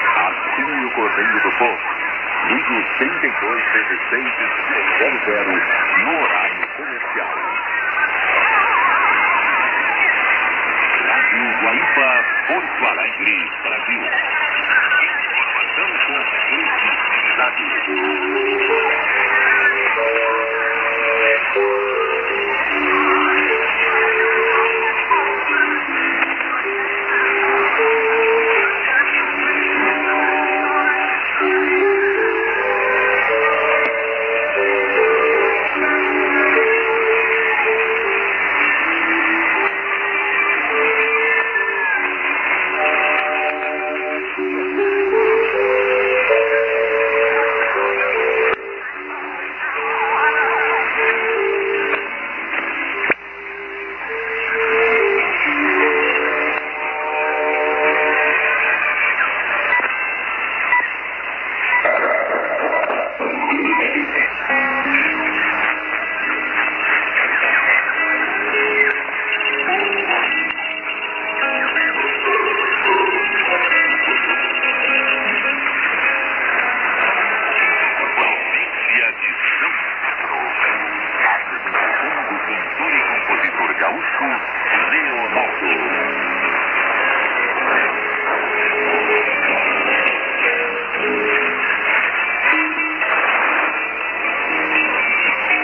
->ID(man)->music(SJ??)->01'05":TS->　10" ＩＤが出ます。その後はＳＴ→ＴＳ